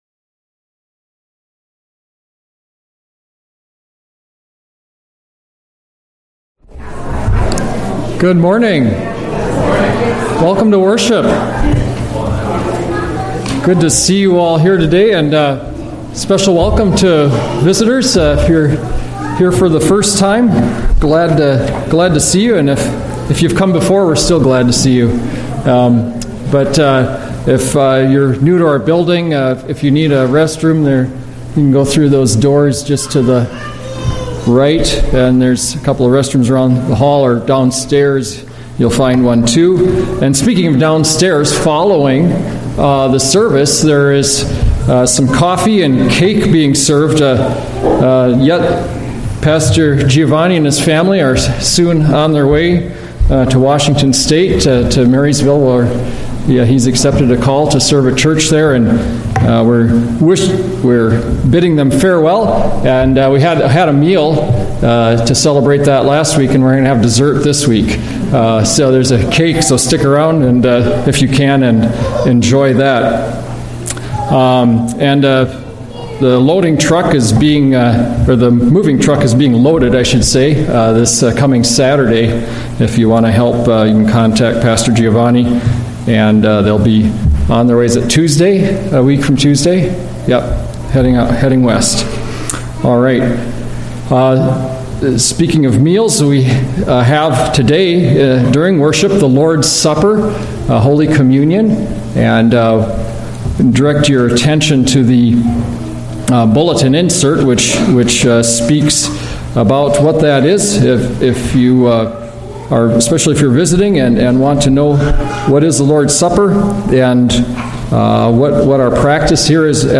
A message from the series "Sunday Worship."